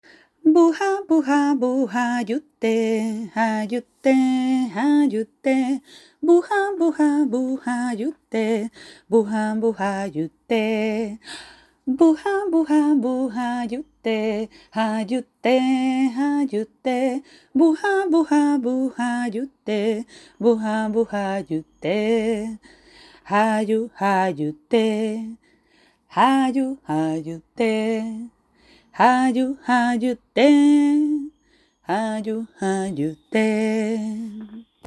Dórico y doble.